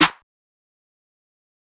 Clap (17).wav